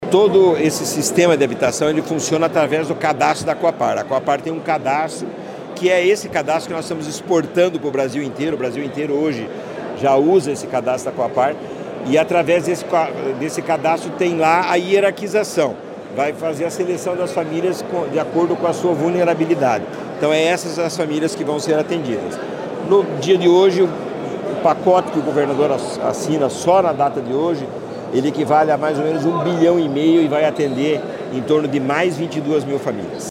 Sonora do presidente da Cohapar, Jorge Lange, sobre o anúncio de R$ 100 milhões para apoiar os municípios paranaenses na regularização fundiária